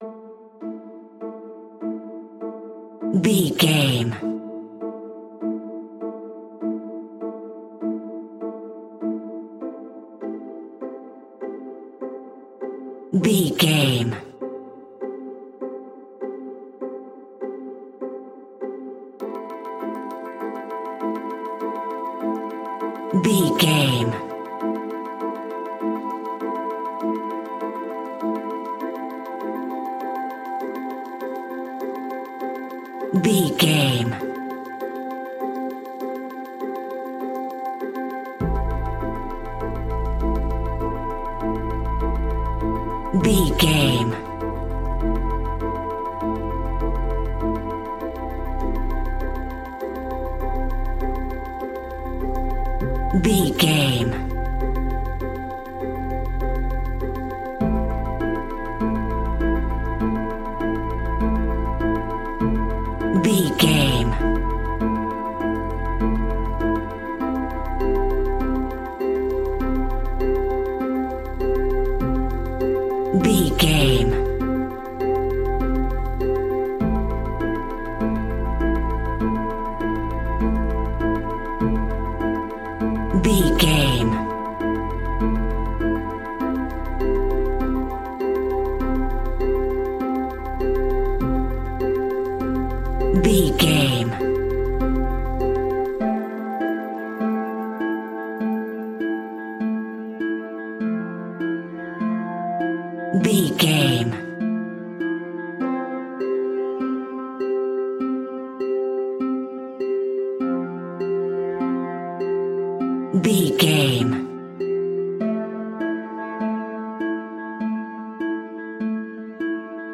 Ionian/Major
scary
ominous
dark
haunting
eerie
synthesizer
drum machine
horror music
Horror Pads